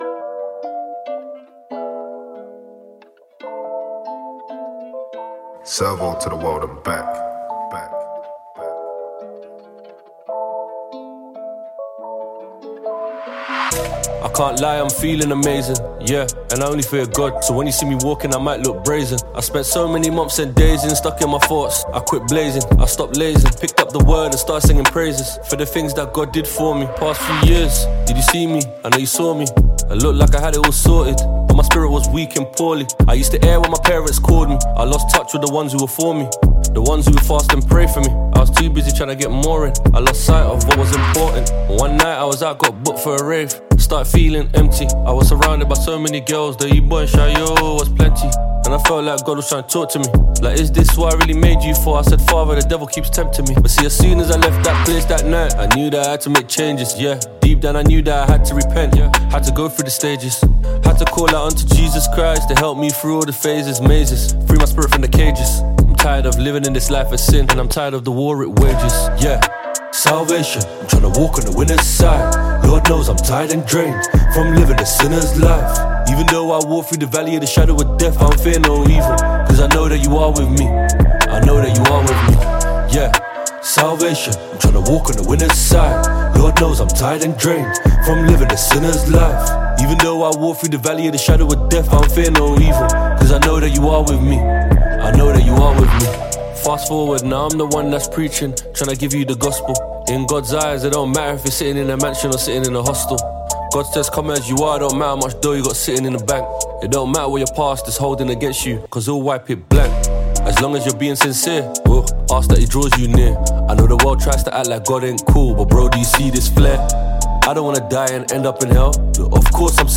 gbedu